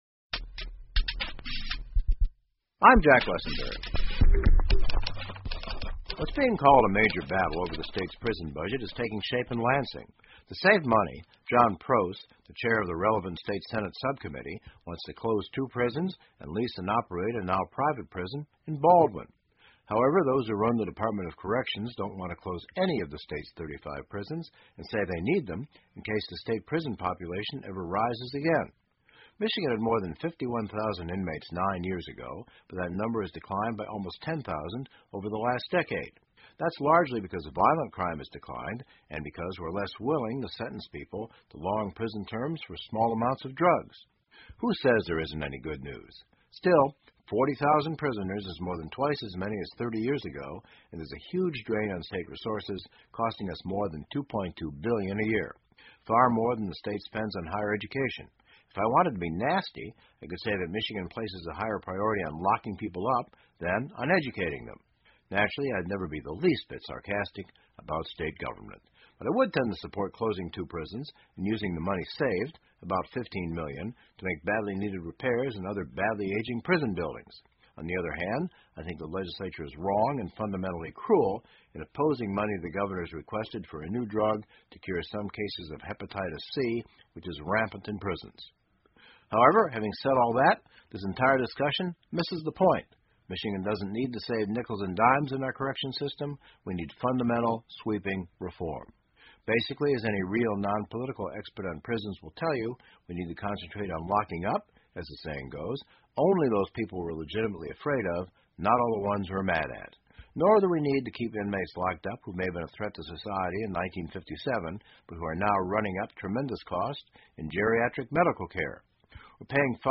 密歇根新闻广播 :监狱占预算太多,密歇根州打算关掉几个 听力文件下载—在线英语听力室